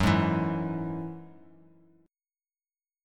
F#7sus2 chord